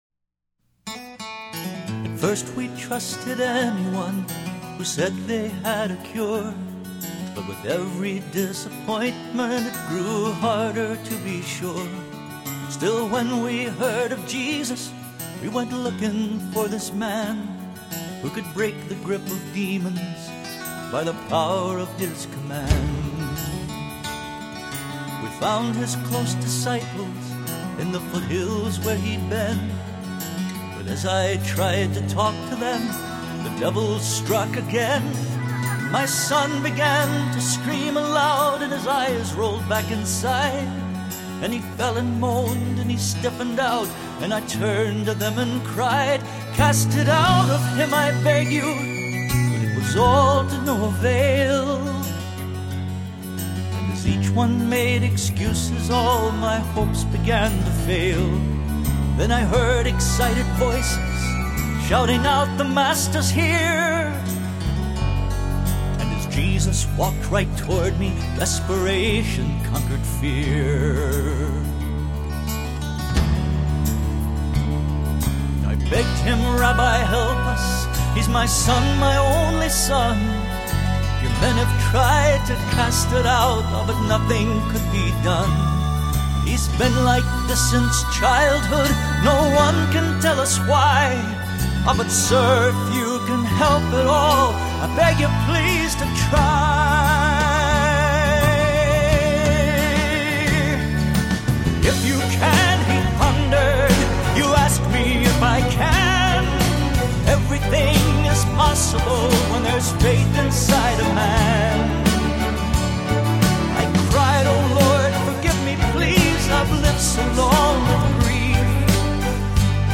here’s a story song about the man in Mark 9:24